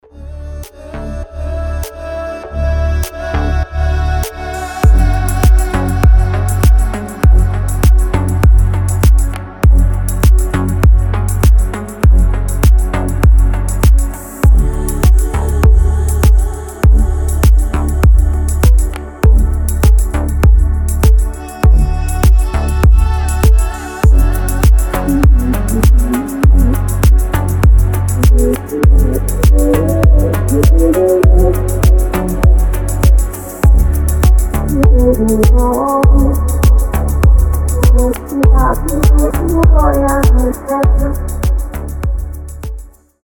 • Качество: 320, Stereo
deep house
атмосферные
релакс